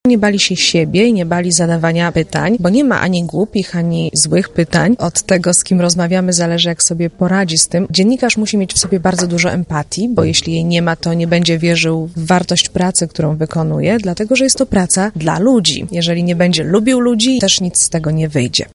Na Uniwersytecie Marii Curie – Skłodowskiej rozpoczął się cykl spotkań z osobami które mogą być inspiracją dla studentów uczelni.